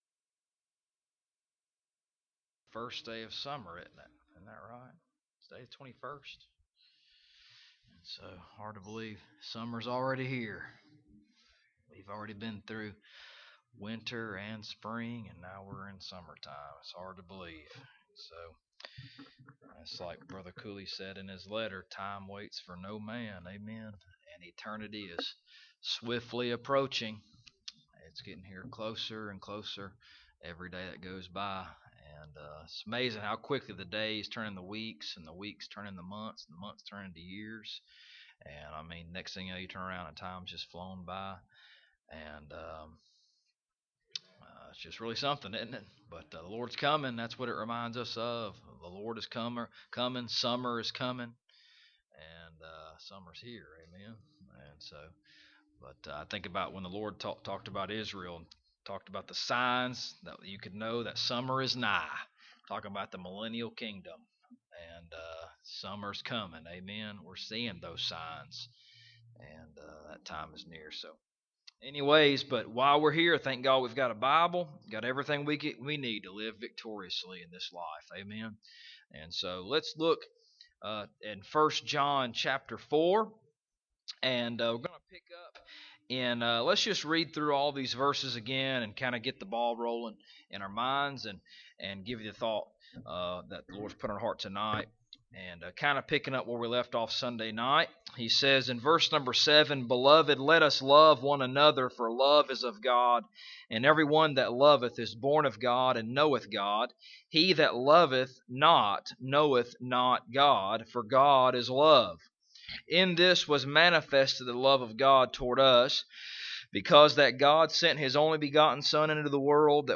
1 John 4:7-19 Service Type: Wednesday Evening « Let Us Love One Another Pt.2 Praise